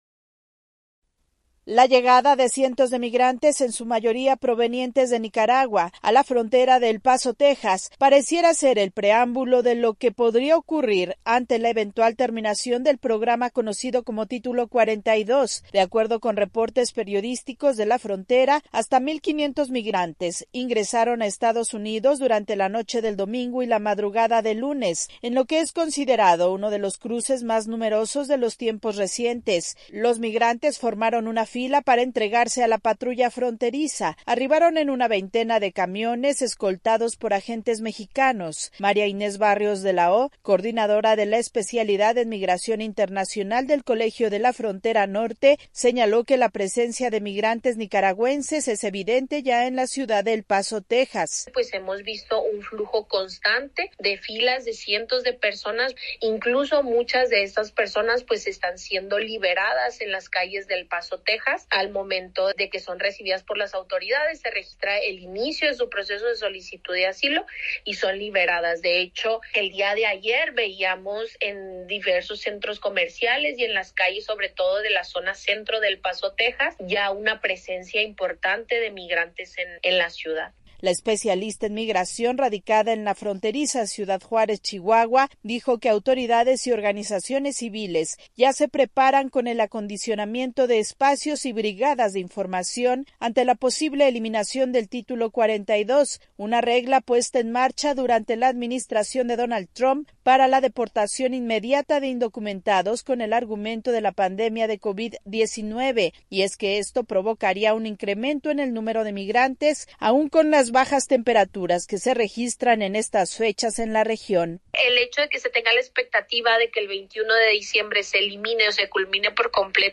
La frontera de México con Estados Unidos experimenta un alto flujo de migrantes y las autoridades temen que se incremente debido a la proximidad de la finalización del Título 42. Desde Ciudad de México informa la corresponsal de la Voz de América